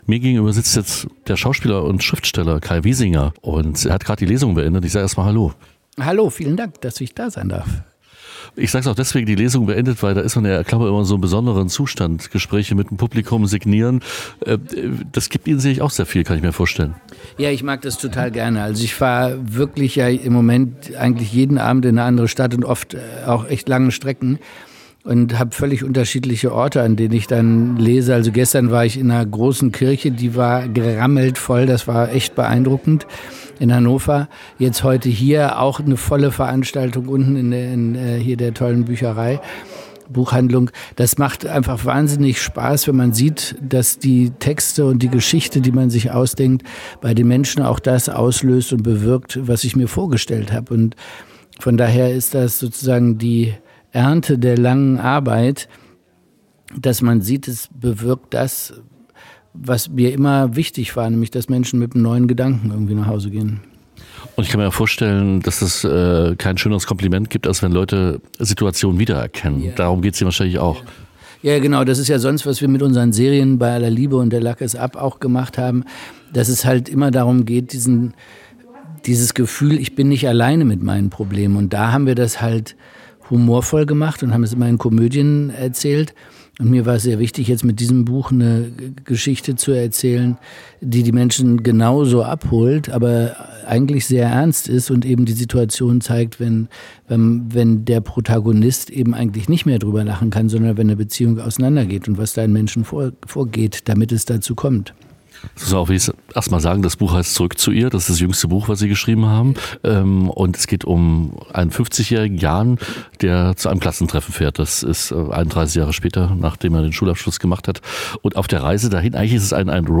Zurück in die Vergangenheit - ein Gespräch mit dem Schauspieler Kai Wiesinger
INTERVIEW Kai Wiesinger.mp3